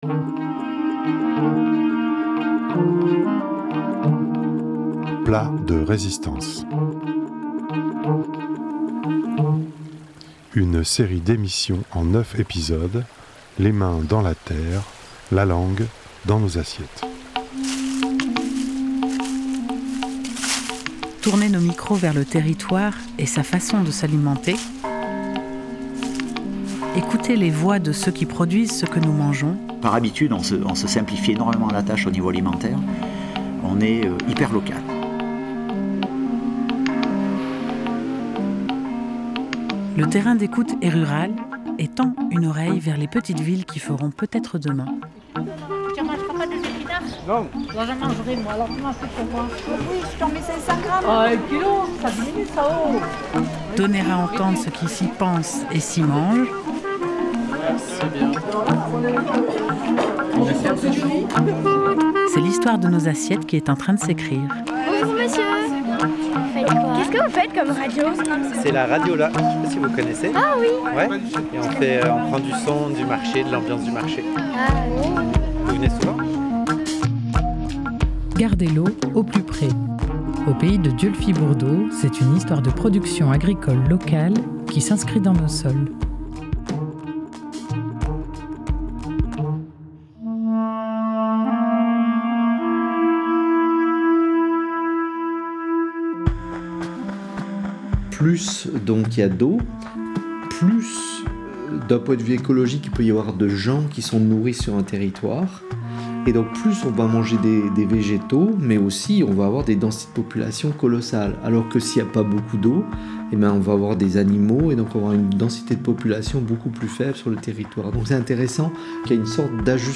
Extrait du film Manon des sources, réalisé par Claude Berri d’après Marcel Pagnol en 1986.